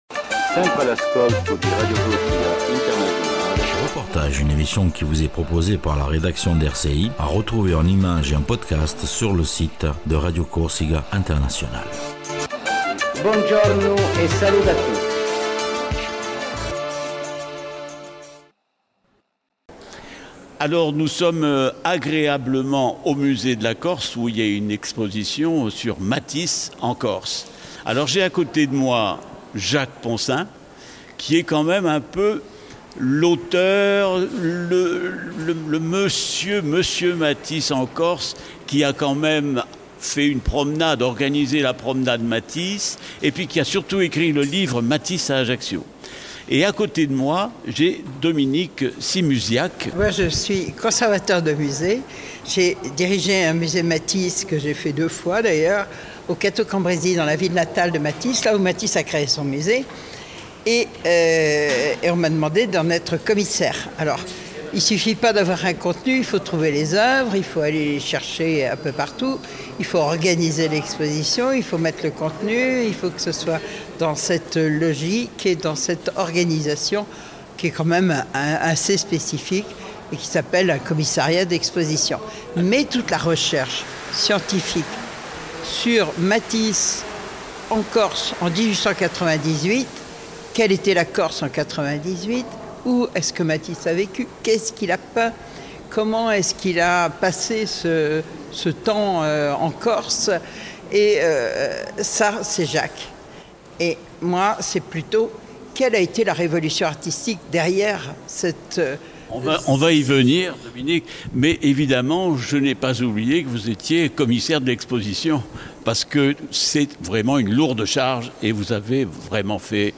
REPORTAGE MATISSE EN CORSE